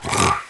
Heroes3_-_Infernal_Troglodyte_-_DefendSound.ogg